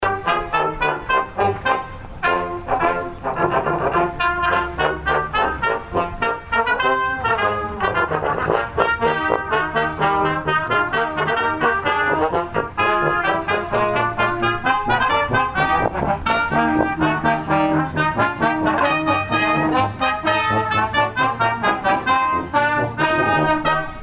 Before the main concert started, the popular area Brass Quintet, Jive for Five, did a pre-concert performance.
trumpet
French Horn
trombone
tuba
ending of Rough Riders March
Listen to Jive for Five playing portions of  Rough Riders March.